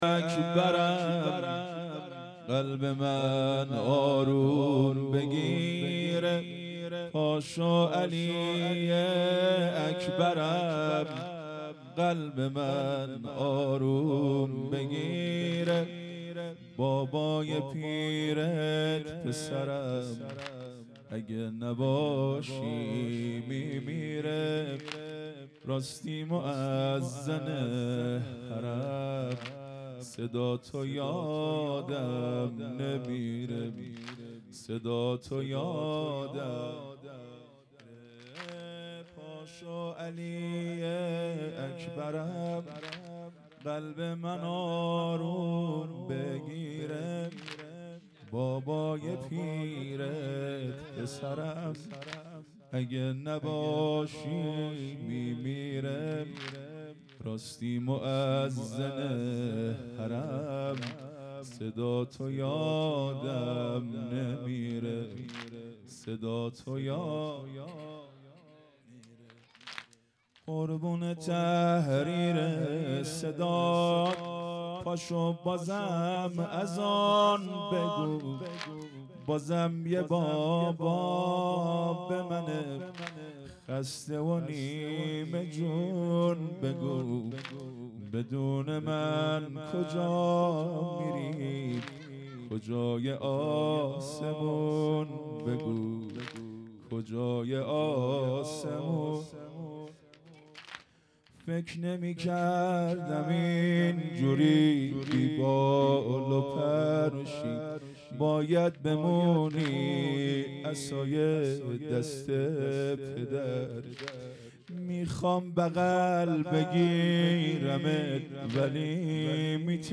واحد | پاشو علی اکبرم| مداح
شب هشتم_ محرم۱۴۴۵